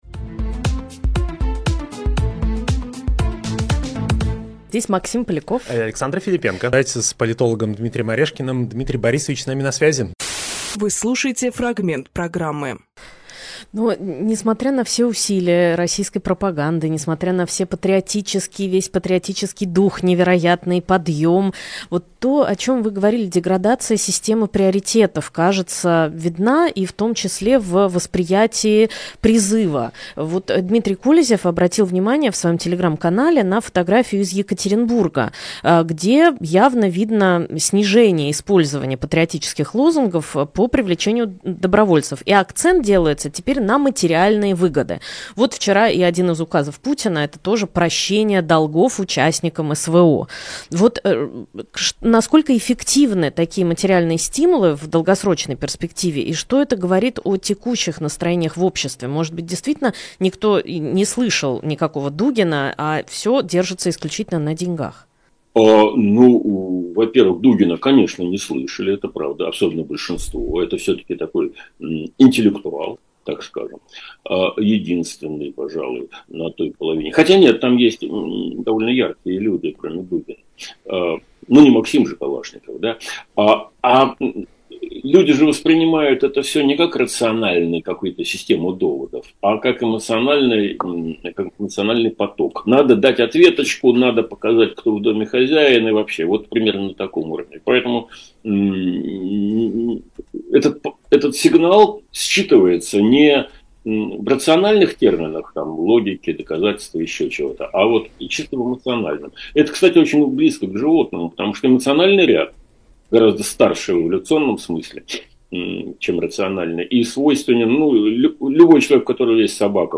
Дмитрий Орешкинполитолог
Фрагмент эфира от 24.11.24